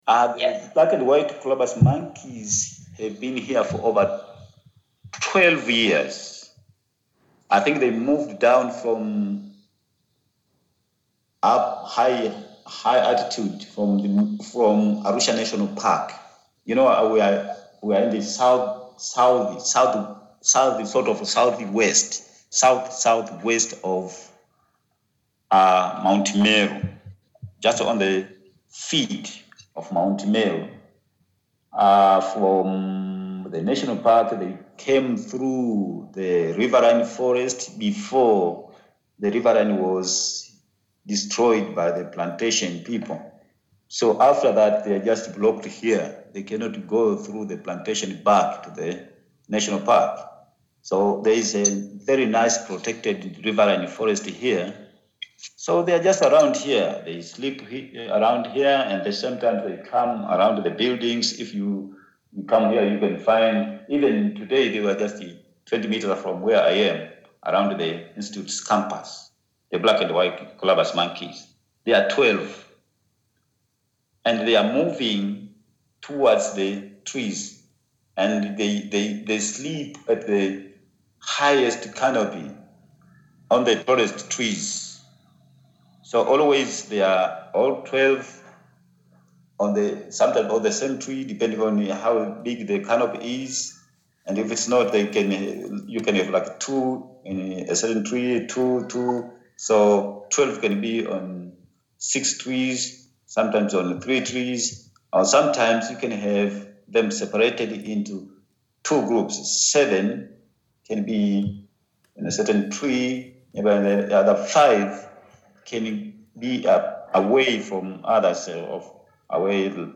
colobus_monkey_family.mp3